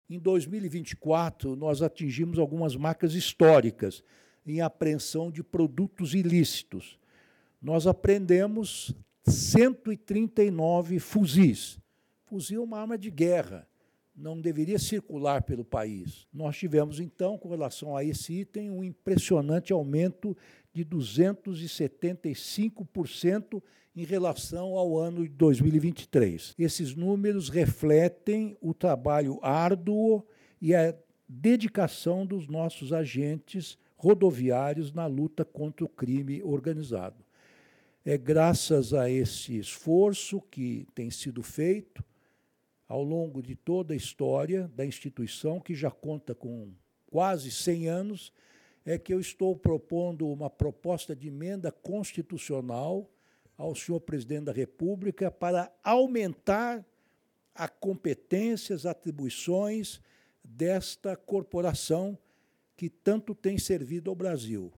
Ministro Ricardo Lewandowski fala sobre o balanço das ações da Polícia Rodoviária Federal no ano de 2024 — Ministério da Justiça e Segurança Pública
ministro-ricardo-lewandowski-fala-sobre-o-balanco-das-acoes-da-policia-rodoviaria-federal-no-ano-de-2024.mp3